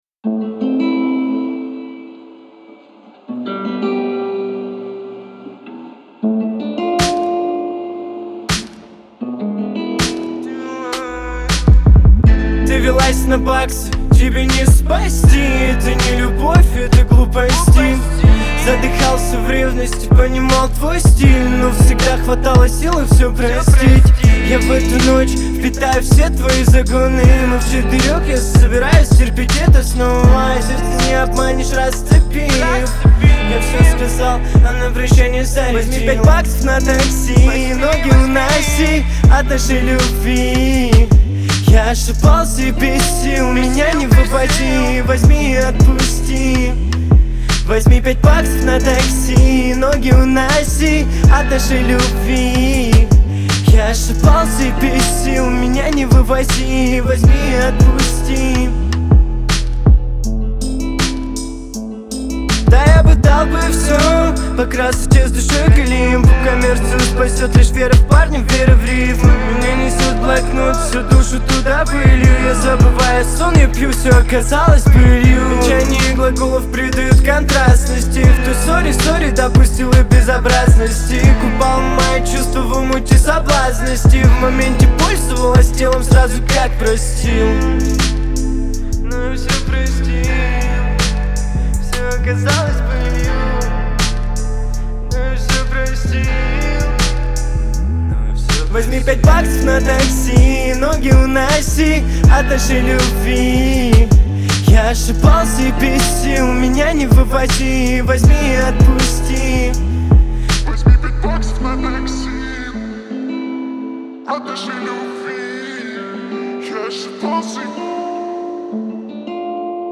• Категория: Русские песни